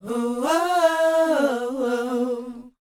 WHOA B C U.wav